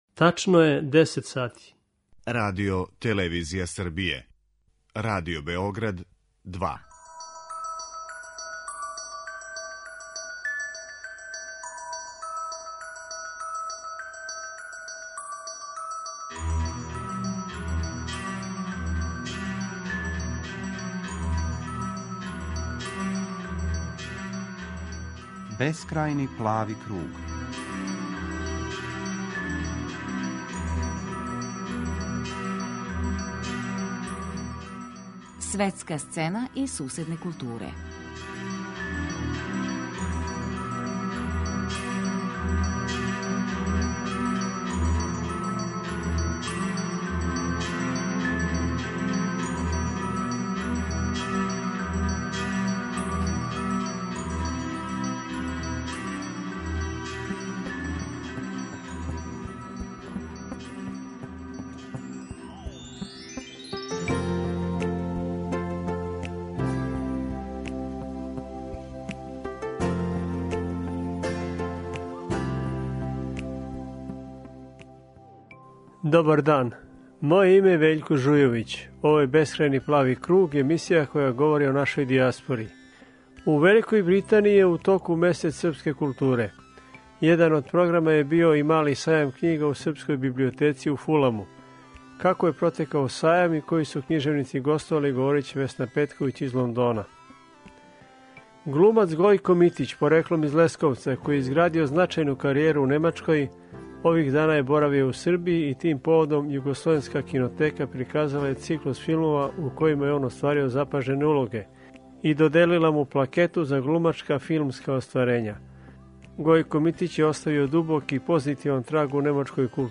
Чућемо разговор са Гојком Mитићем о његовом животу и раду у Немачкој.